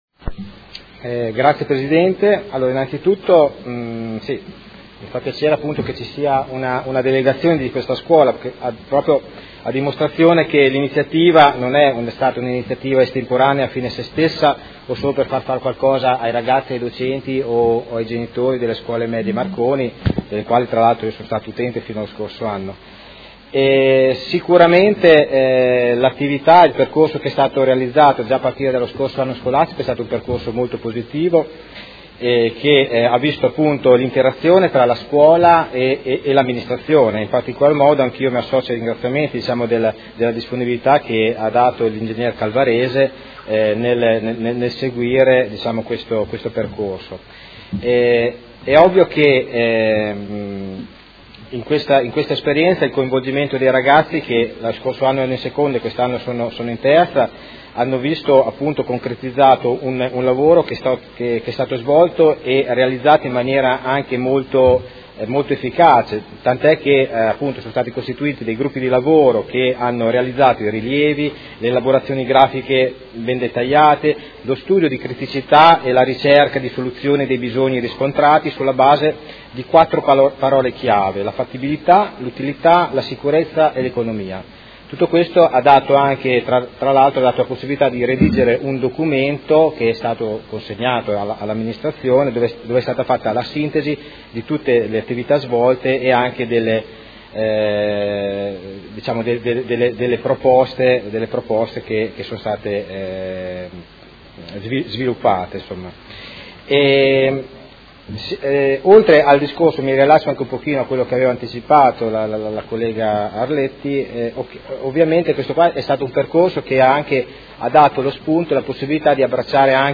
Seduta del 19/05/2016. Interrogazione dei Consiglieri Arletti e Stella (P.D.) avente per oggetto: Progetto di riqualificazione parcheggio antistante la scuola Media Marconi elaborato dagli alunni.